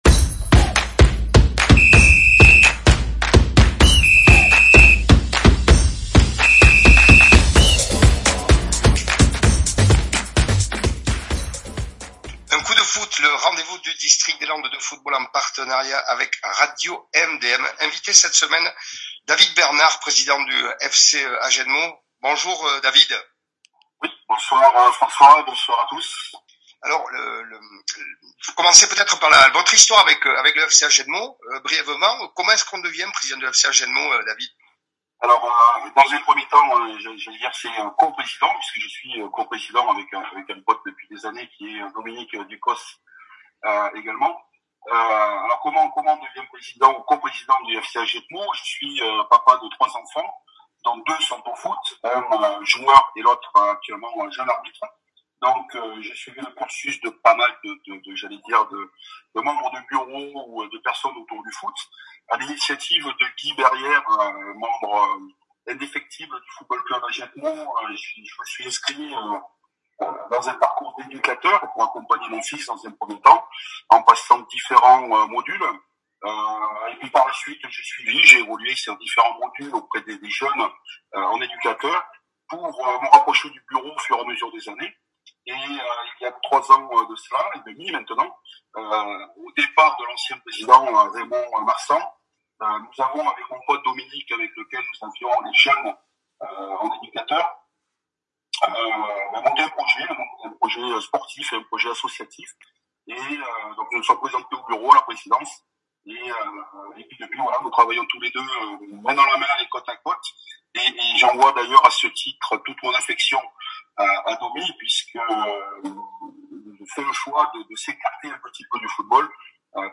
Retrouvez l’interview complète sur le podcast « Un coup de Foot ».